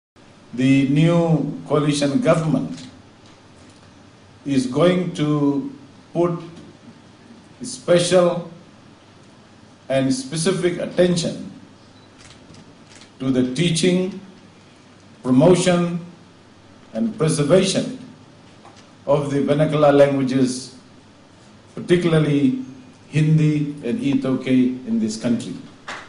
He made this statement at the launching of the upcoming 12th World Hindi Conference at Civic center last night.